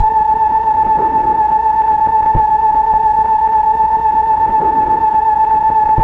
Index of /90_sSampleCDs/Keyboards of The 60's and 70's - CD1/KEY_Optigan/KEY_Optigan Keys